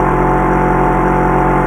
KART_Engine_loop_2.ogg